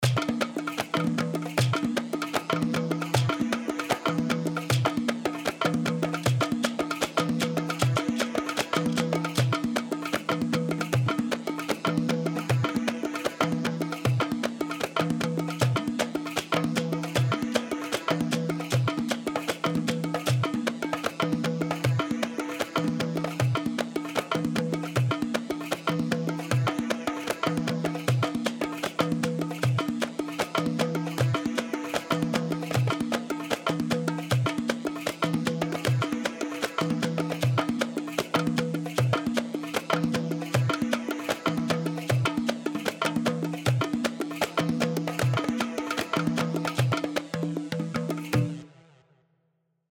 Yemeni
Gheylee 4/4 154 غيلي
Gheylee-Yamani-4-4-154.mp3